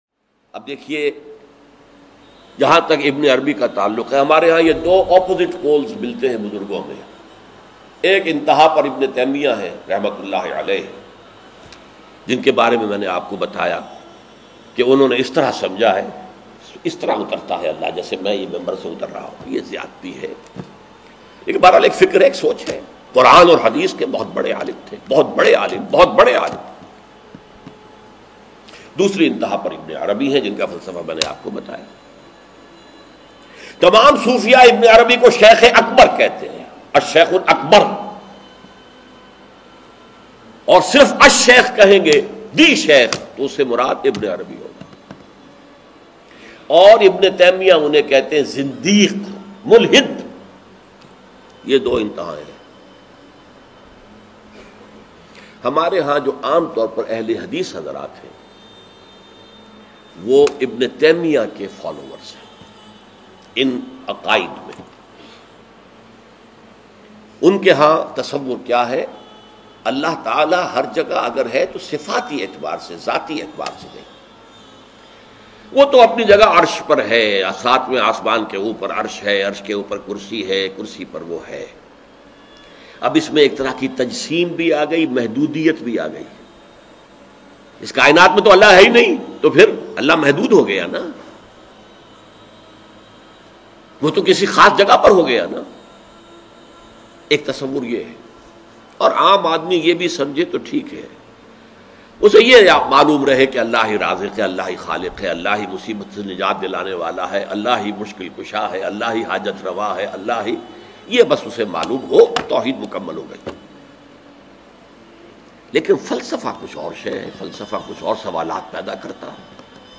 Dr Israr Ahmed R.A a renowned Islamic scholar.